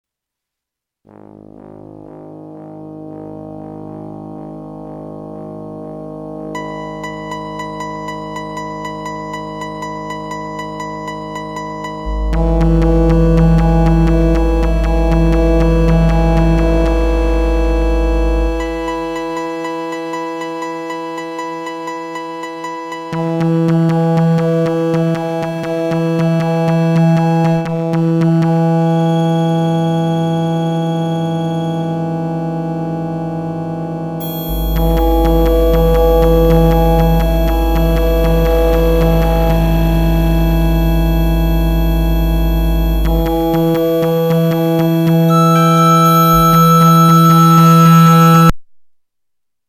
mGen is a music composition machine that reacts to listener preference in the generation of compositions.
Random notes, durations and sequences are used to generate an initial set of machines.